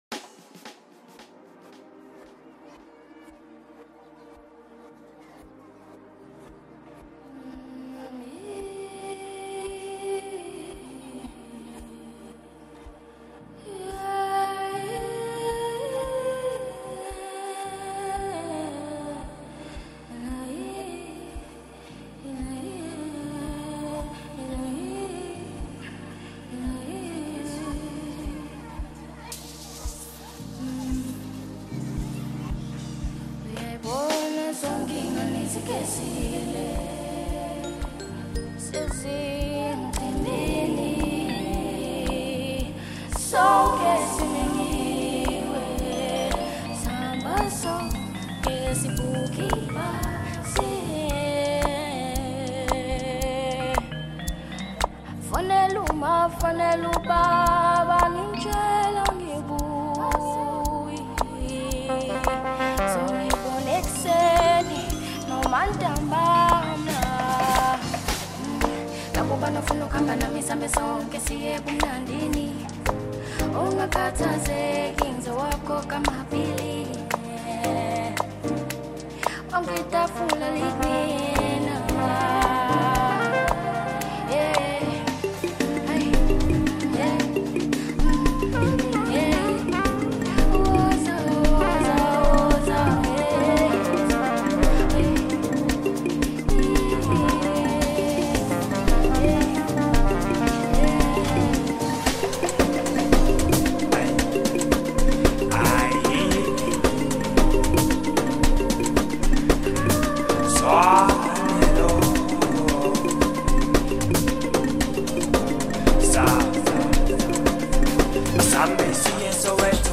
Maskandi, DJ Mix, Hip Hop